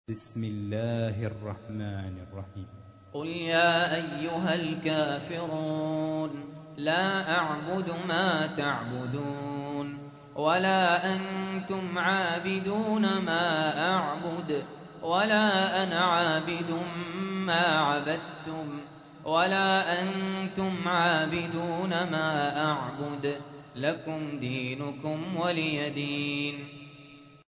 تحميل : 109. سورة الكافرون / القارئ سهل ياسين / القرآن الكريم / موقع يا حسين